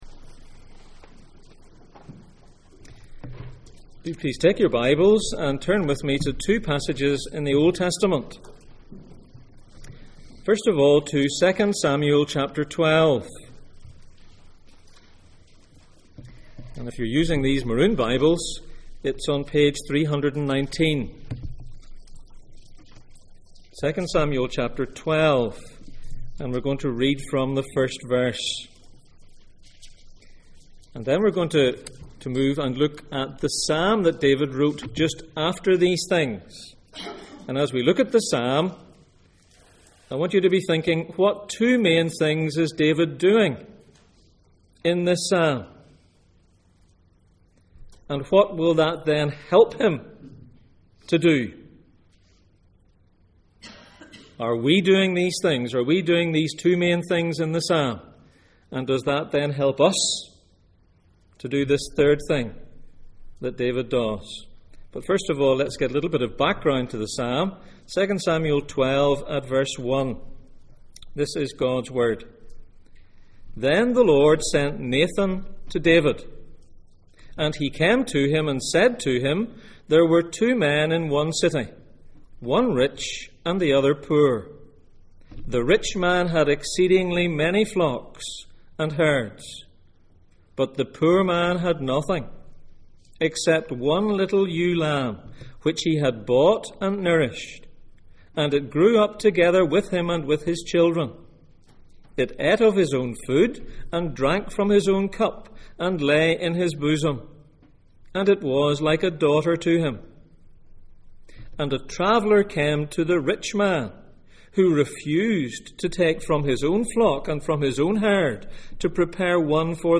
Psalm 51:1-19 Service Type: Sunday Morning %todo_render% « Why am I not ashamed of the Gospel of Christ?